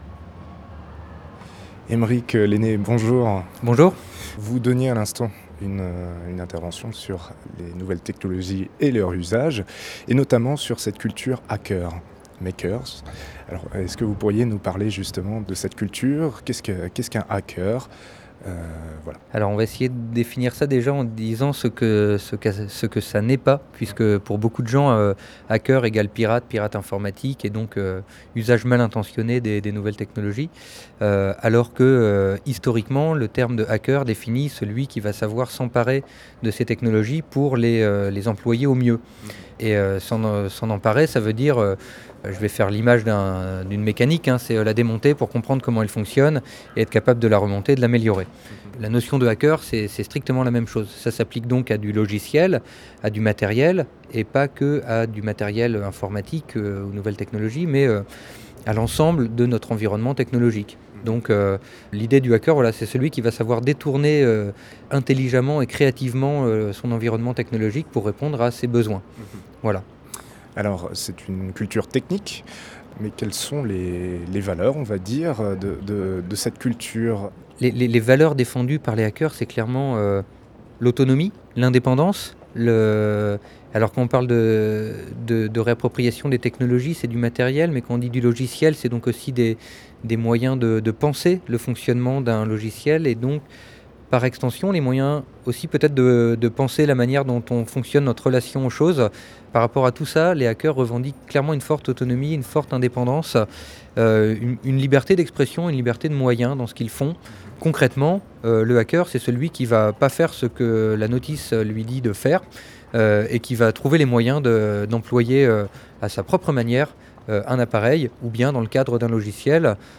Entretien réalisé à l’occasion du festival Viva-Cité organisé au Liberté à Rennes. http